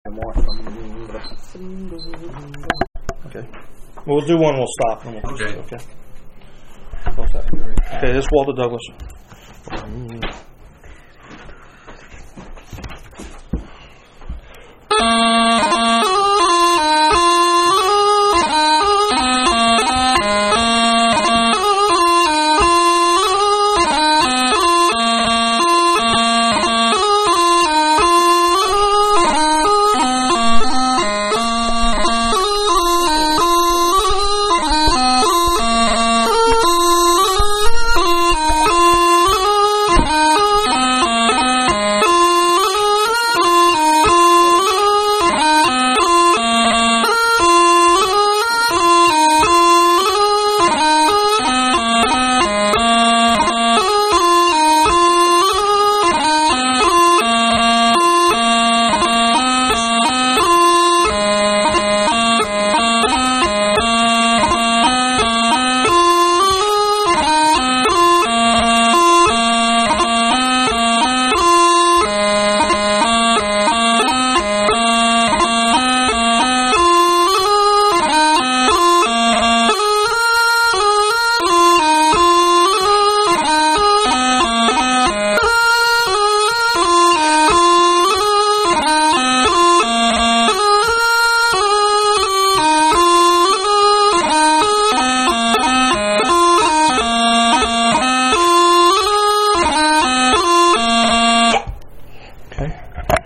March 80 bpm